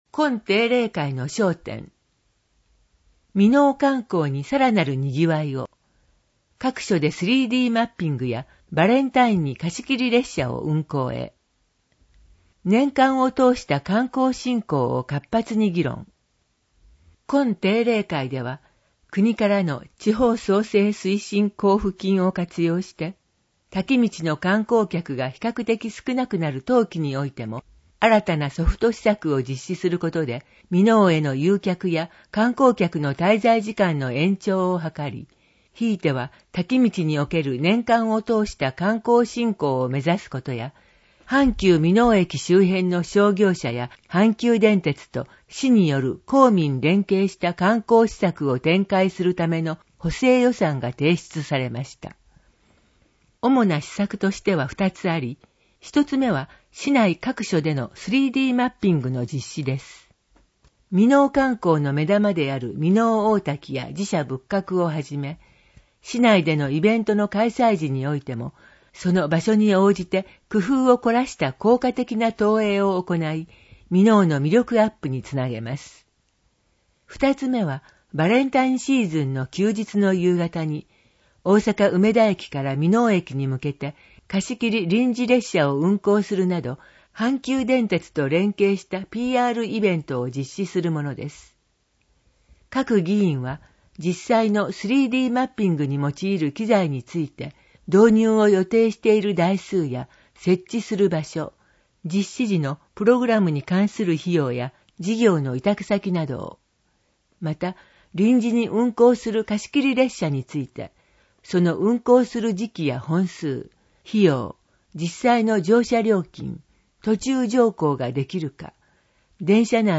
みのお市議会だより「ささゆり」の内容を声で読み上げたものを掲載しています。